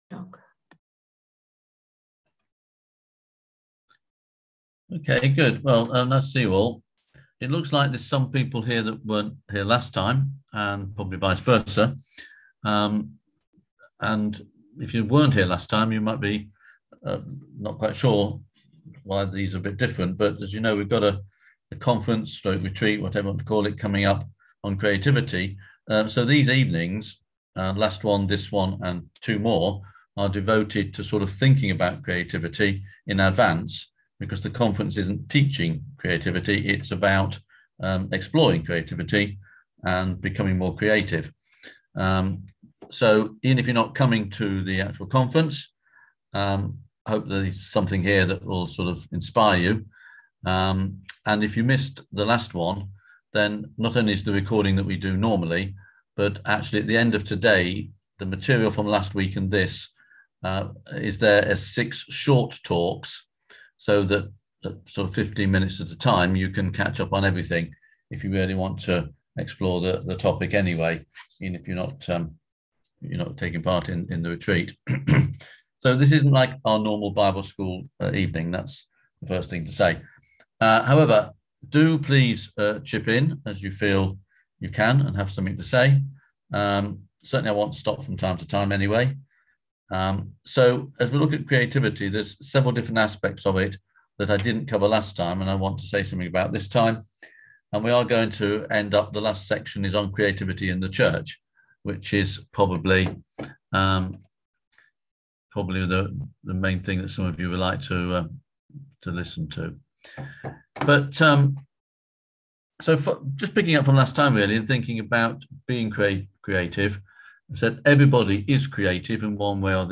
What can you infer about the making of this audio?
On February 10th at 7pm – 8:30pm on ZOOM